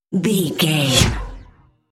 Pass by sci fi fast
Sound Effects
futuristic
car
vehicle